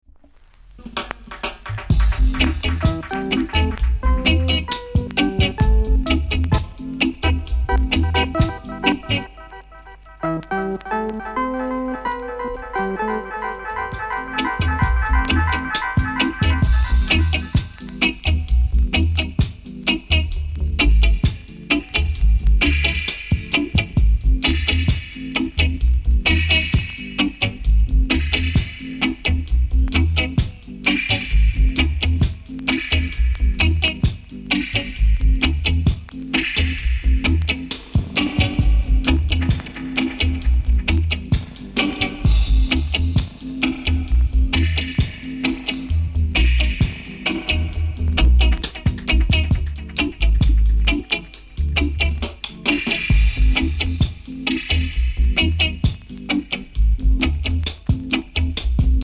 ※チリ、パチノイズが少しあります。
HEAVY MELODICA INST!!!